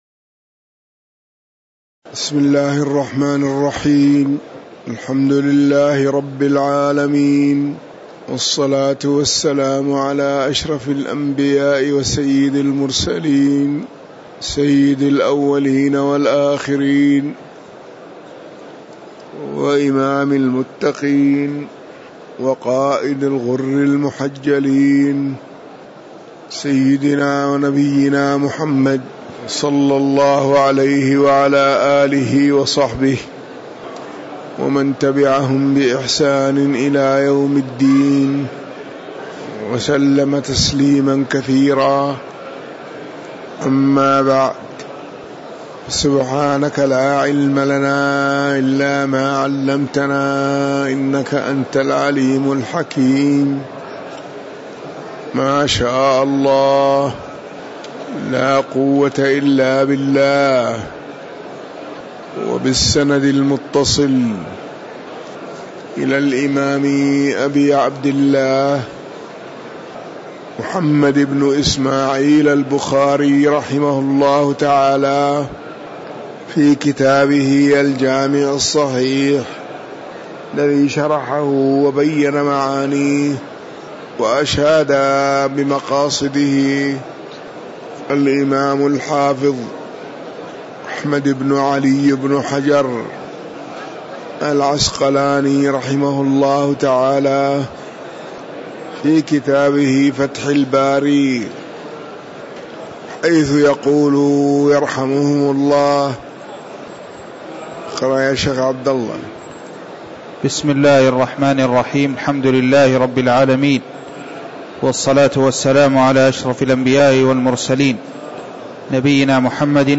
تاريخ النشر ١٢ ربيع الثاني ١٤٤١ هـ المكان: المسجد النبوي الشيخ